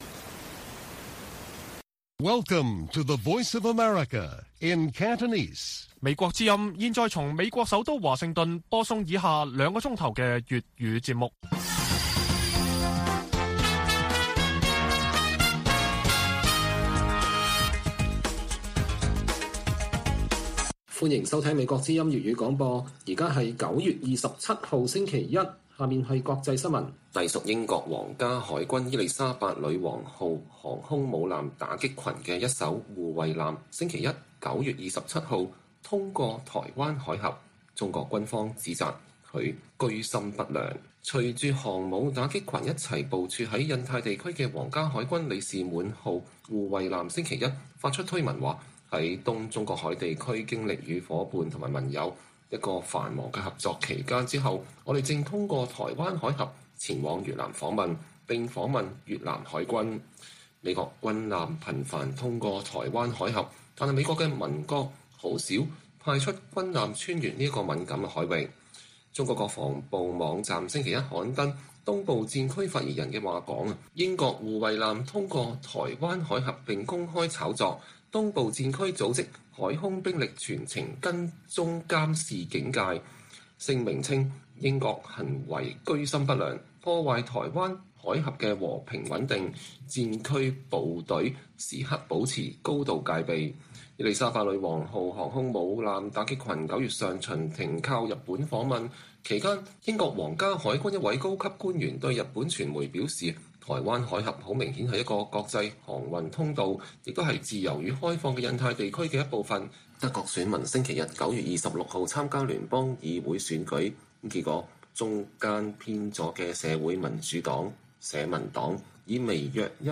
粵語新聞 晚上9-10點: 英國軍艦穿越台灣海峽